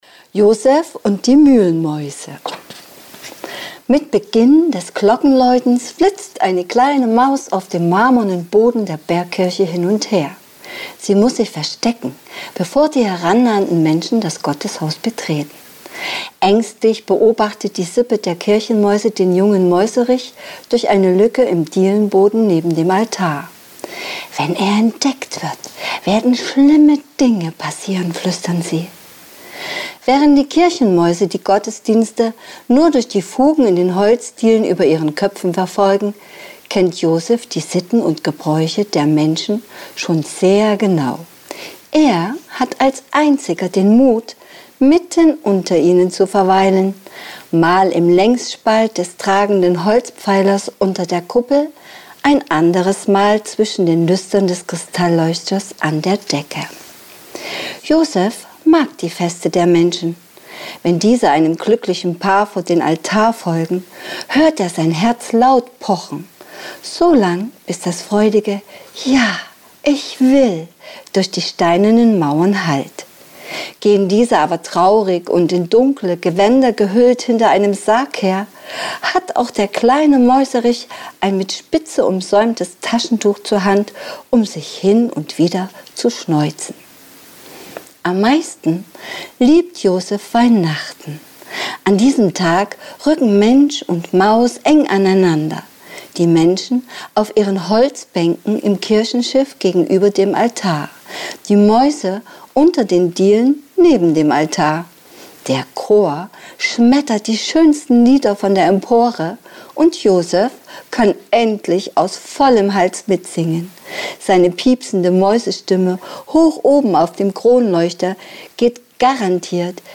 Leseprobe